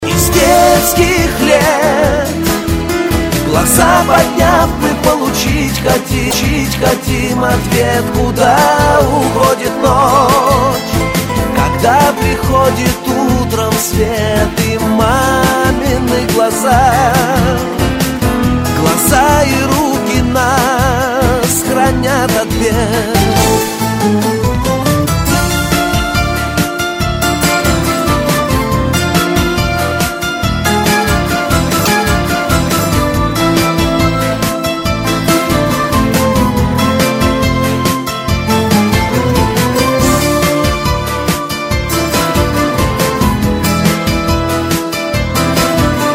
из Шансон
Категория - шансон.